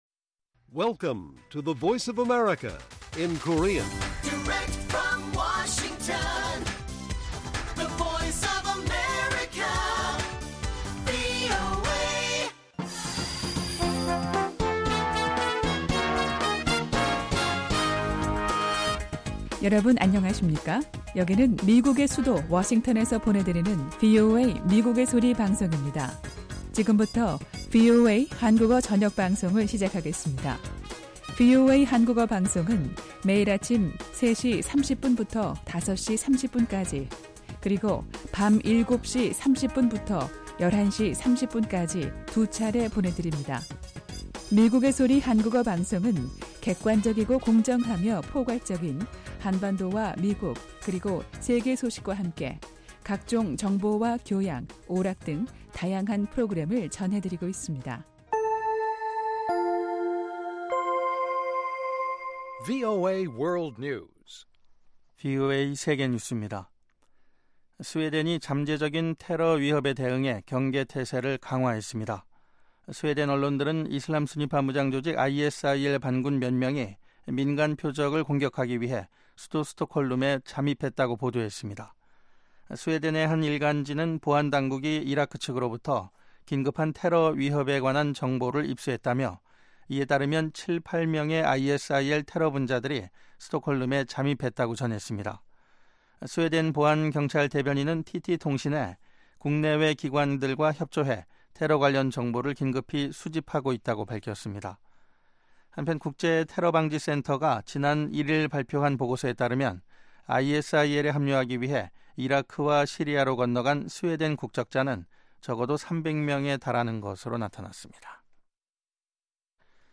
VOA 한국어 방송의 간판 뉴스 프로그램 '뉴스 투데이' 1부입니다.